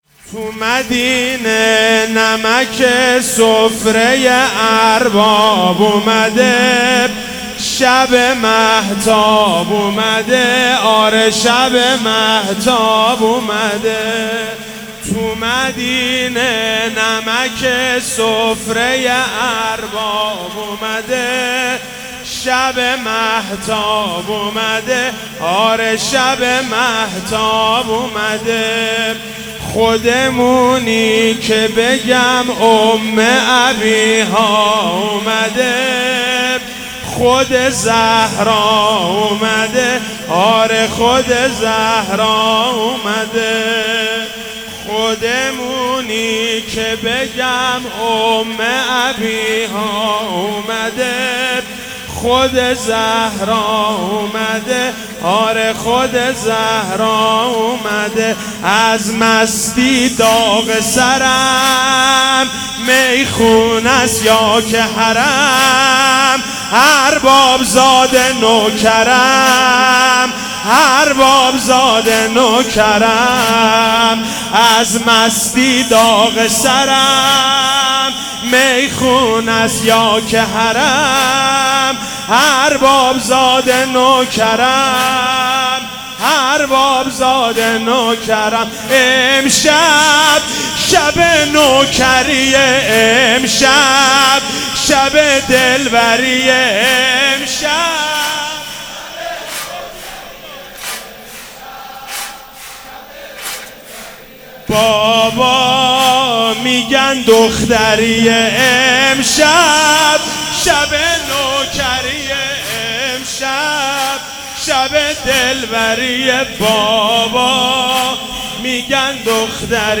ولادت حضرت رقیه سلام الله علیها
سرود مولودی